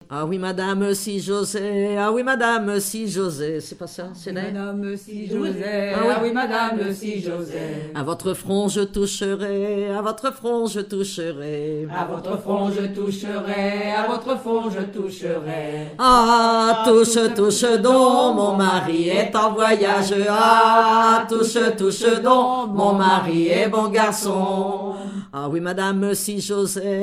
Genre énumérative
collectif de chanteuses de chansons traditionnelles
Pièce musicale inédite